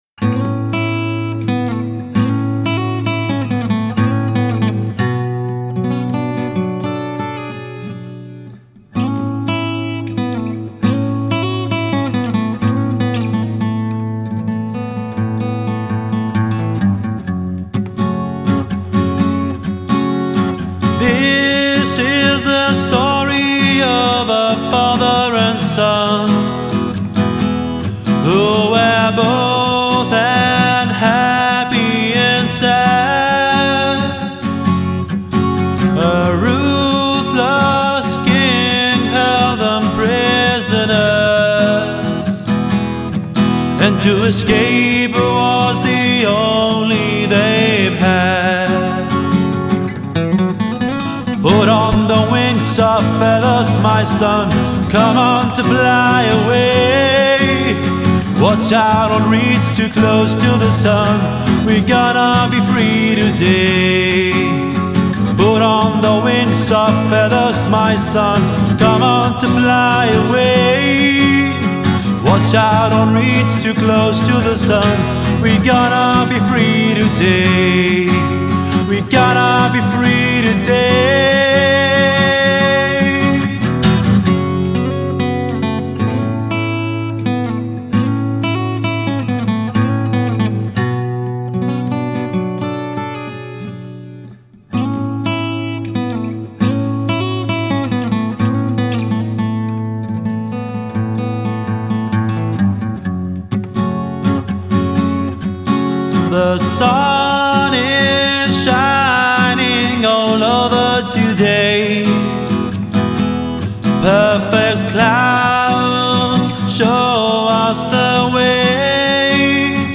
ζωντανή εκτέλεση με κιθάρα στο στούντιο
Ακουστική Κιθάρα στο 4